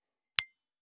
282.食器をぶつける,ガラスをあてる,皿が当たる音,皿の音,台所音,皿を重ねる,カチャ,ガチャン,カタッ,コトン,ガシャーン,カラン,カタカタ,
コップ